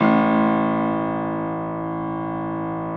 53g-pno01-A-1.wav